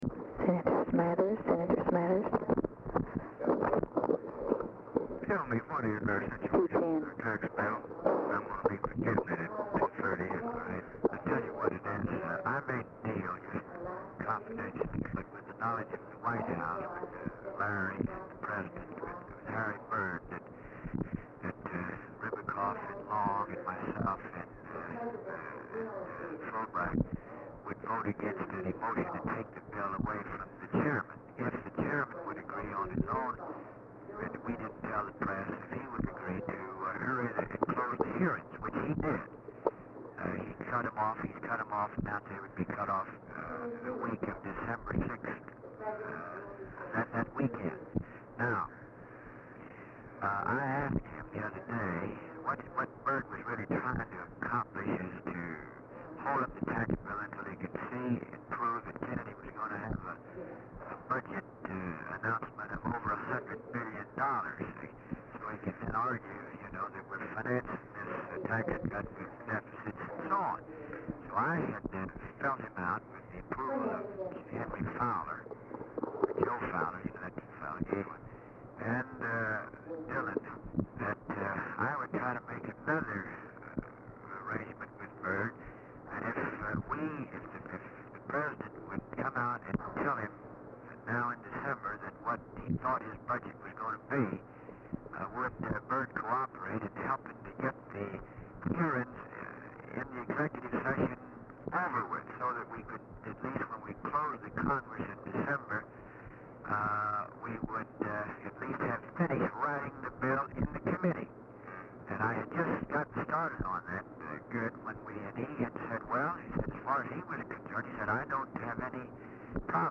Telephone conversation # 16, sound recording, LBJ and GEORGE SMATHERS, 11/23/1963, 2:10PM
DAT RECORDING MADE FROM REEL-TO-REEL TAPE RECORDED FROM ORIGINAL BELT IN ATTEMPT TO PROVIDE IMPROVED SOUND QUALITY
INAUDIBLE OFFICE CONVERSATION IN BACKGROUND